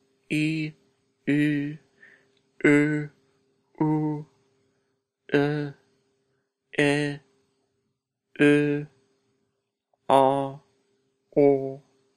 Vowels: